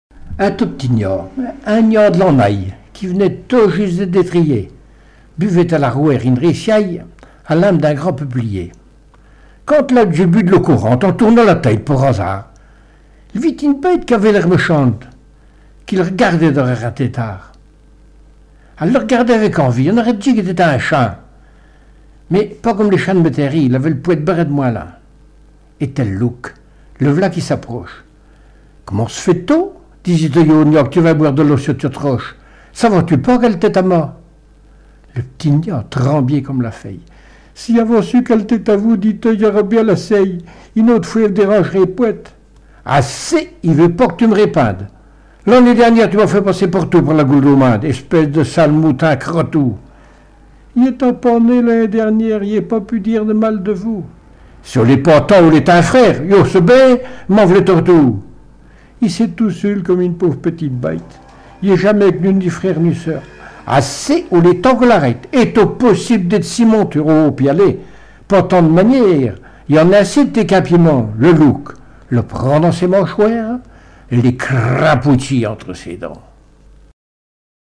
Mémoires et Patrimoines vivants - RaddO est une base de données d'archives iconographiques et sonores.
Genre fable
Catégorie Récit